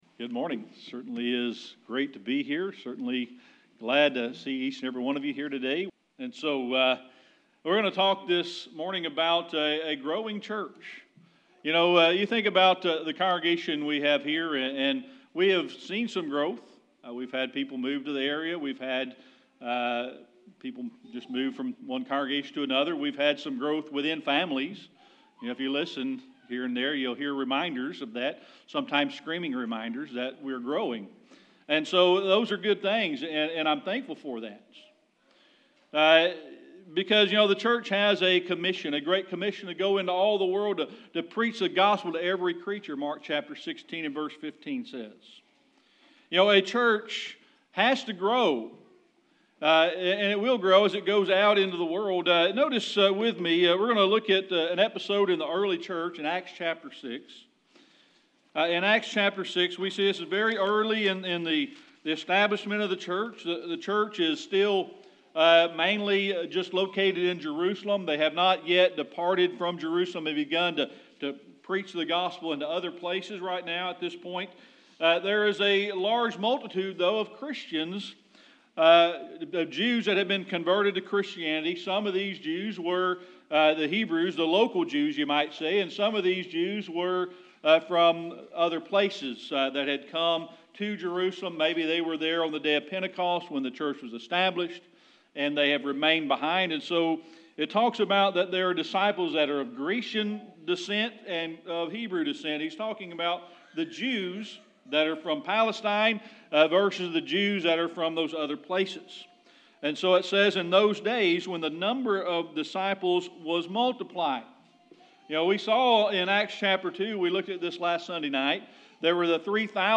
Passage: Mark 16:15 Service Type: Sunday Morning Worship We're going to be talking about a growing church.